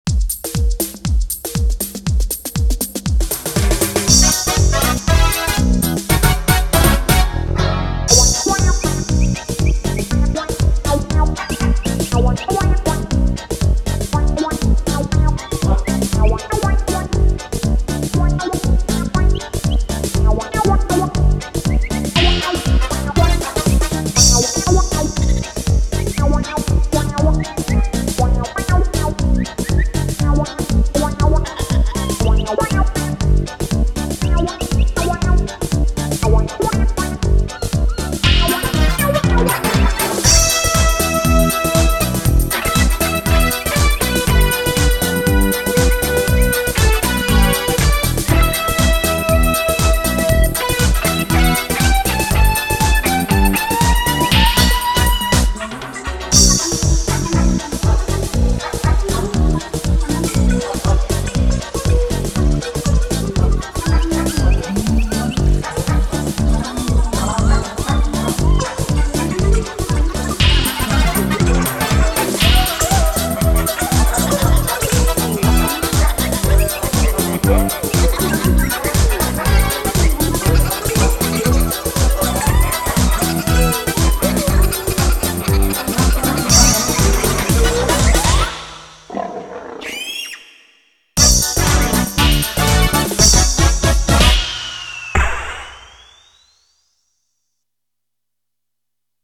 [カラオケ]
長さ１分半の一聴ふざけたような曲ですが、後半の携帯（一昔の）の音を聴いて、「あれっ」と思われる方は鋭い！そう、この曲はただの動物園を描いたものではない、今の若年層に対する風刺なのです。